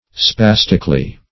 spastically - definition of spastically - synonyms, pronunciation, spelling from Free Dictionary
spastically - definition of spastically - synonyms, pronunciation, spelling from Free Dictionary Search Result for " spastically" : The Collaborative International Dictionary of English v.0.48: Spastically \Spas"tic*al*ly\, adv.